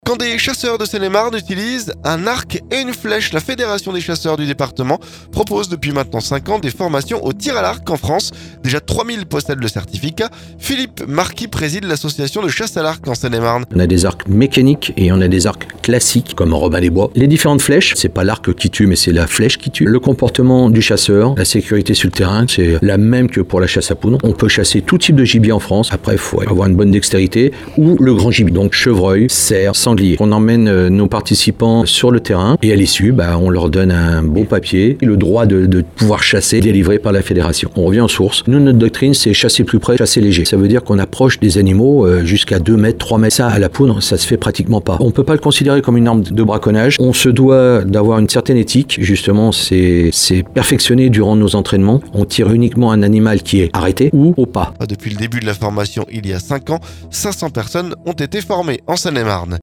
*Reportage